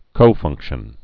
(kōfŭngkshən)